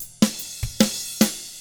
146ROCK F2-R.wav